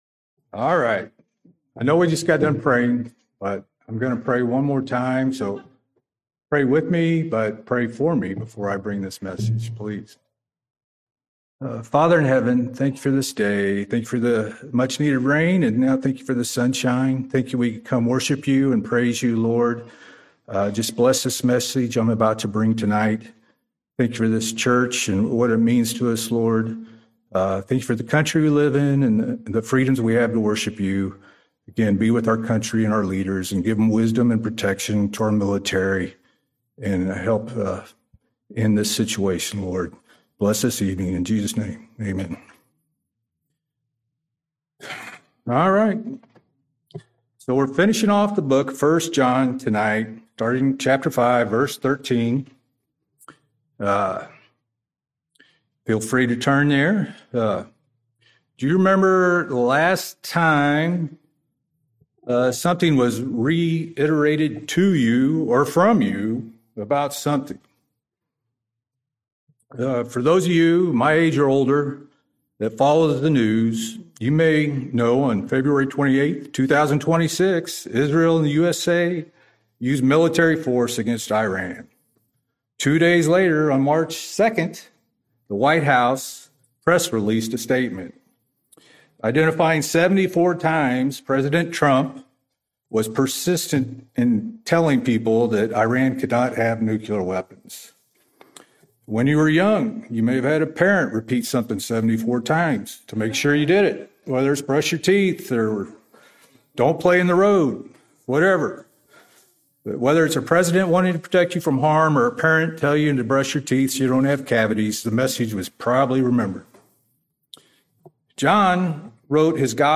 Evening Service ~ 1 John 5:13-21